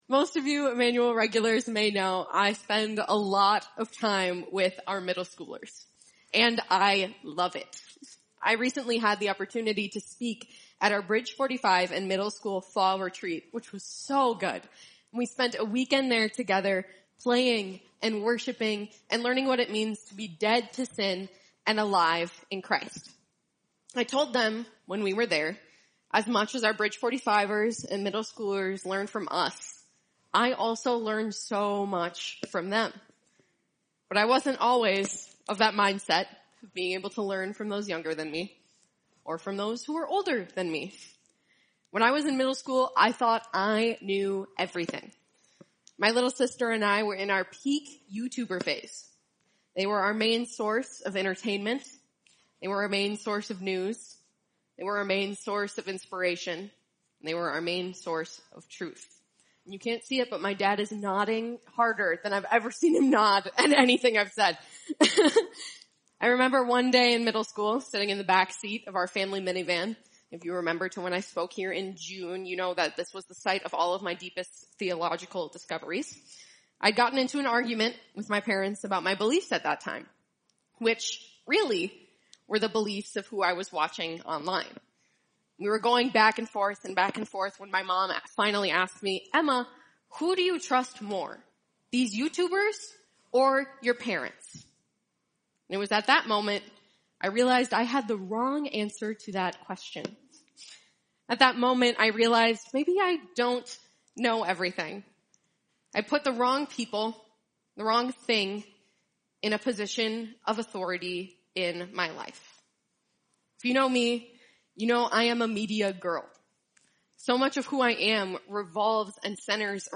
Altar-ed Higher Places Watch Message By